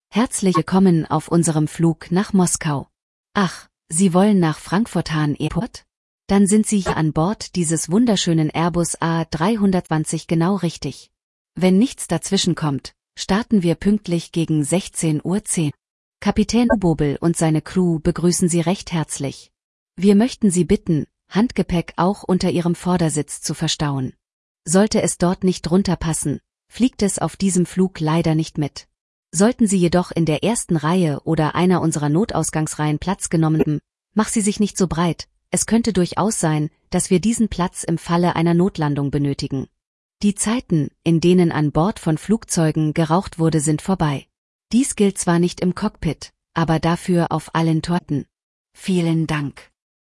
BoardingWelcome.ogg